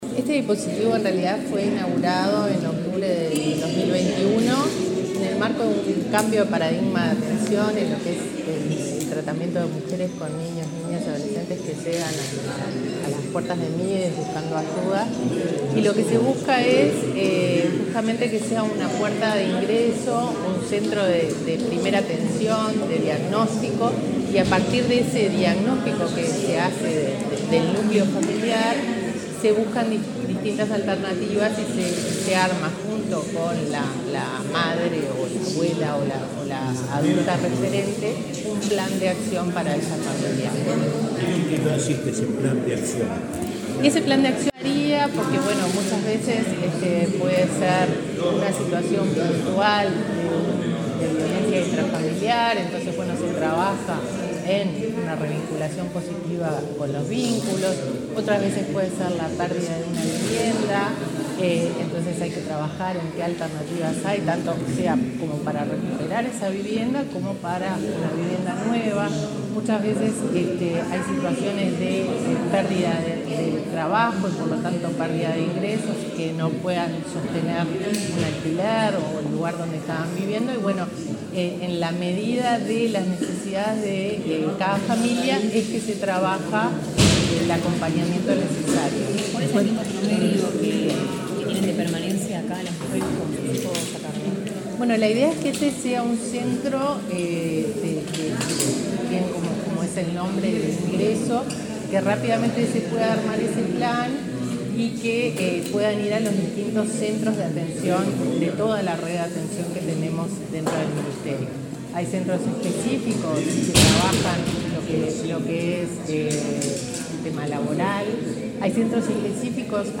Declaraciones de la directora de Protección Social, del Mides, María Fernanda Auersperg
Declaraciones de la directora de Protección Social, del Mides, María Fernanda Auersperg 19/07/2023 Compartir Facebook X Copiar enlace WhatsApp LinkedIn Este miércoles 19, la presidenta en ejercicio, Beatriz Argimón, visitó el centro El Zorzal, del Ministerio de Desarrollo Social (Mides). La directora de Protección Social, de esa cartera, María Fernanda Auersperg, dialogó con la prensa acerca de la función de este dispositivo ubicado en Montevideo.